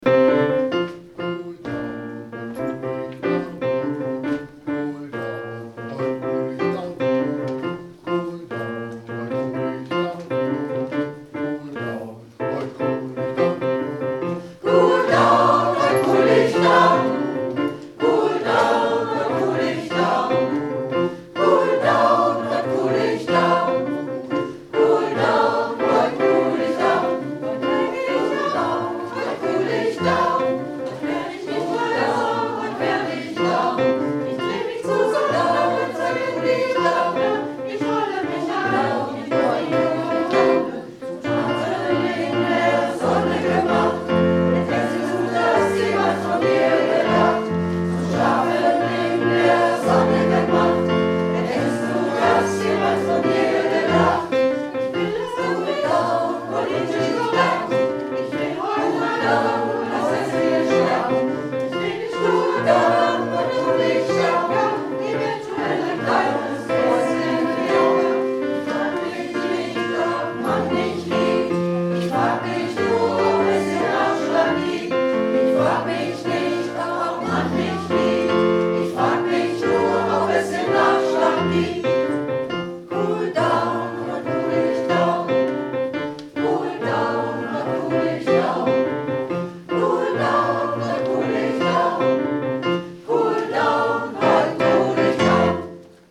Reinhard Mey im Chor
Hörbeispiele vom ersten und zweiten Wochenende: